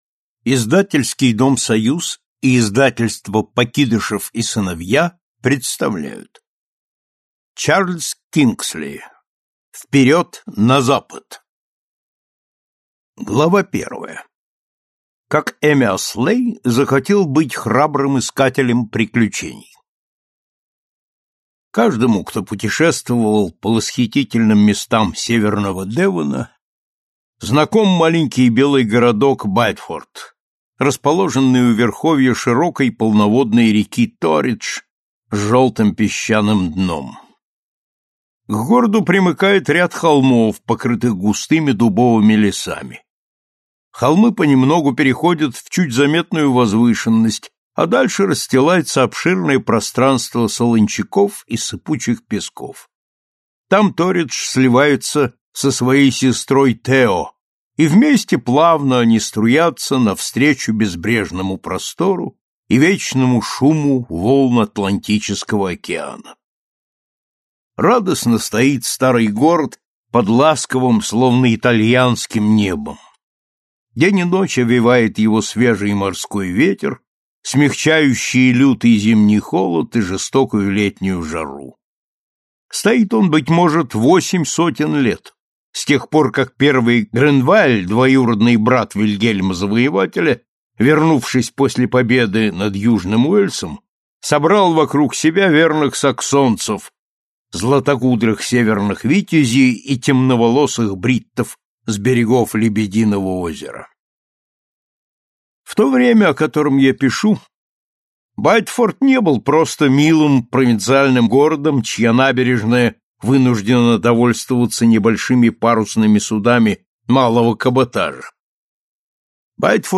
Слушать аудиокнигу Одна смертельная тайна полностью